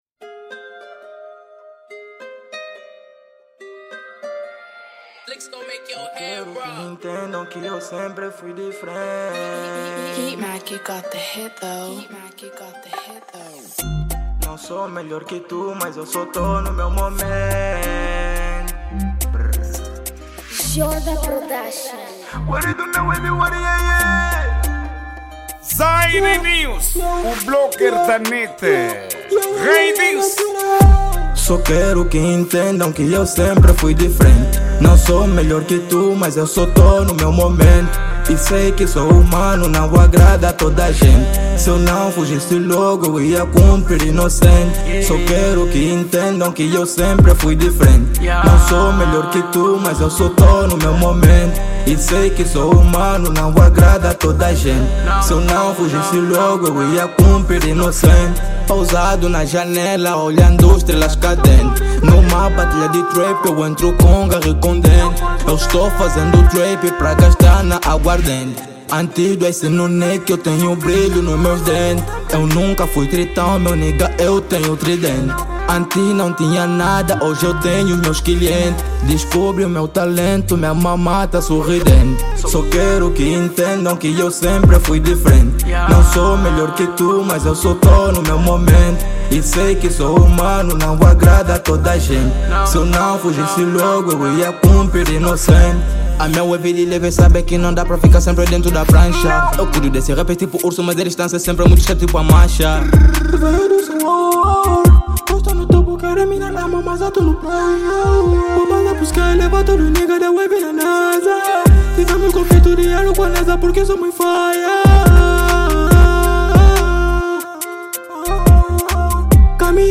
Gênero:Rap